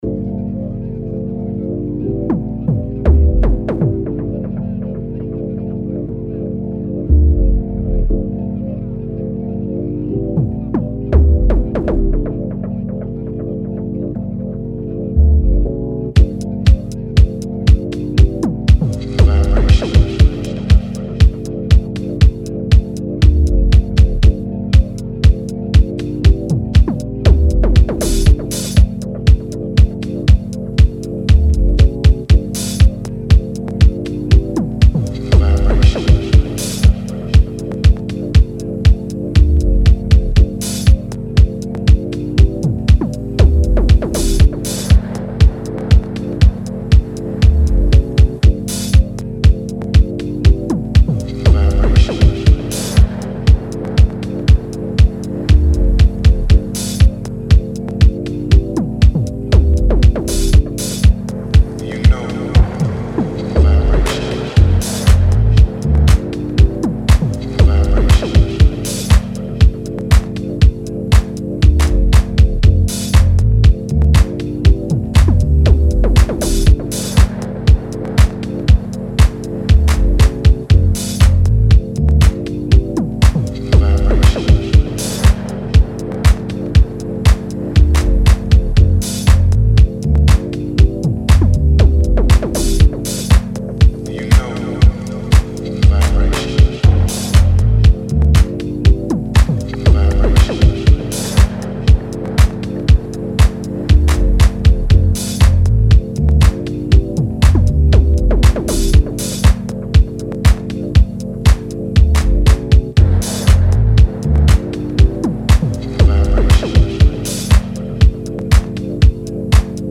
a fresh batch of analogue disco